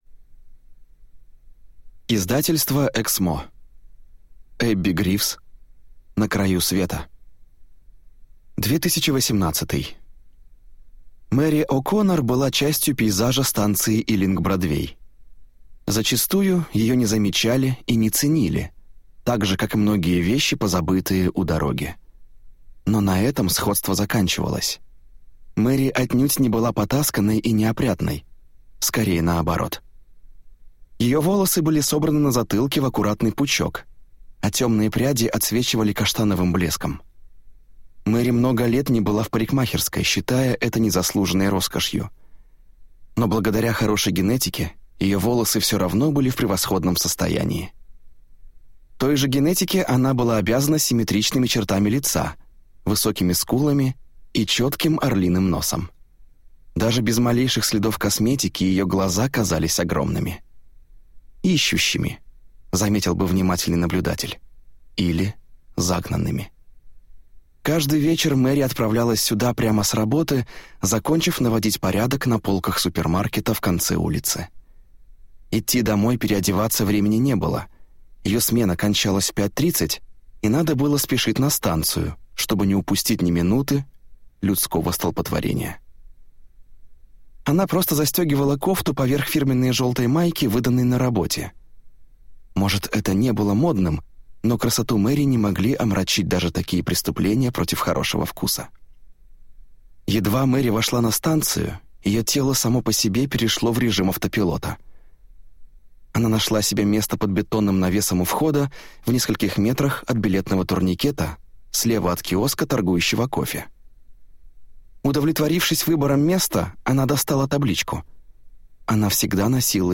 Аудиокнига На краю света | Библиотека аудиокниг